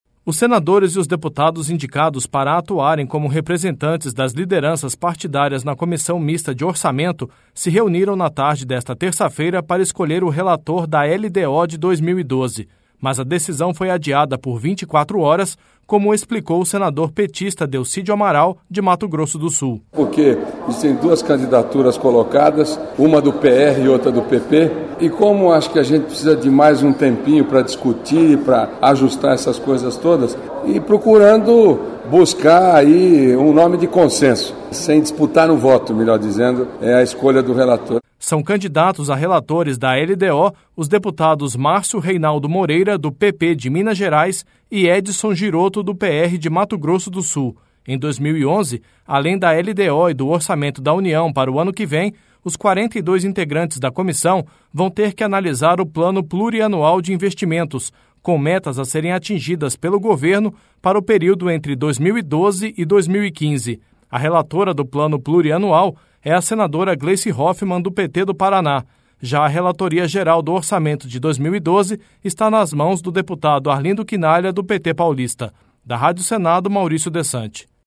Mas a decisão foi adiada por 24 horas, como explicou o senador petista Delcídio Amaral, de Mato Grosso do Sul (DELCÍDIO):